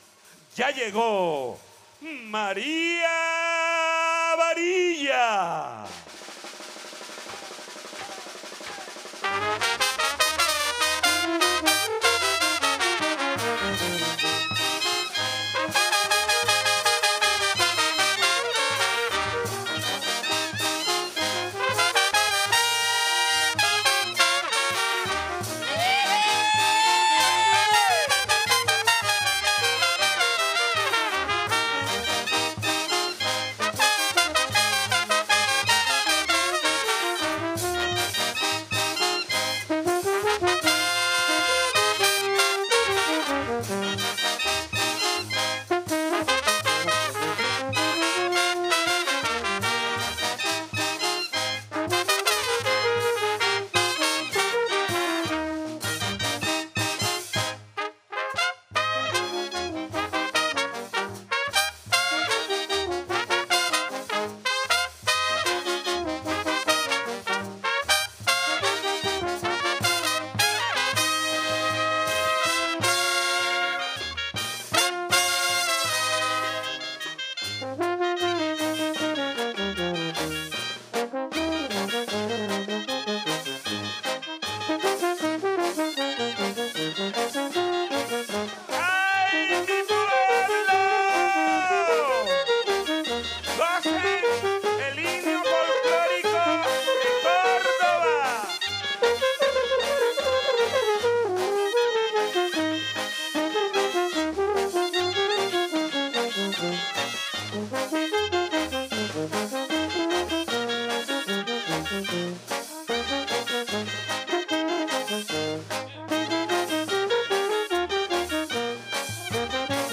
Transcripcion y Descripcion de Nueve Solos Improvisados en el Bombardino en Tres Porros Palitiaos del Repertorio de las Bandas Pelayeras.
Porro, Palitiao, Pelayero, Bandas
ARTES MUSICALES - TESIS Y DISERTACIONES ACADÉMICAS , MÚSICA FOLCLÓRICA - REGIÓN CARIBE (COLOMBIA) , INSTRUMENTOS DE VIENTO , ARREGLOS MUSICALES